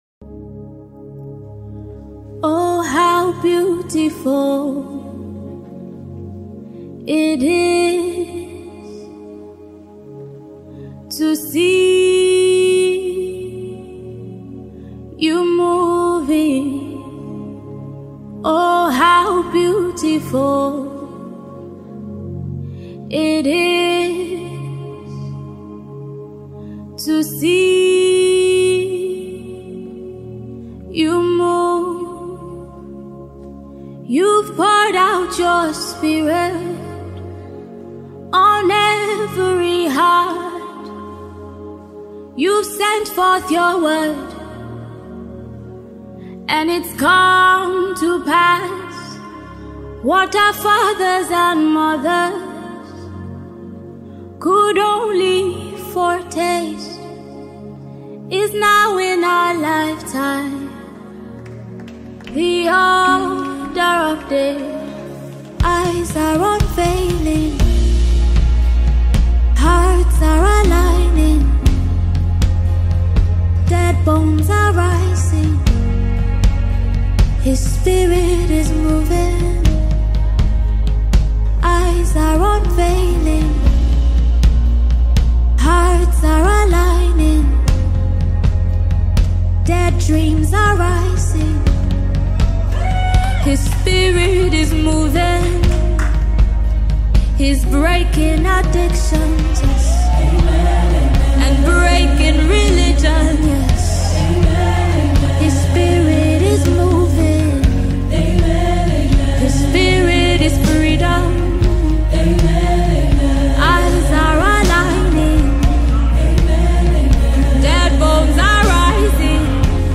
gospel song
an album full of joy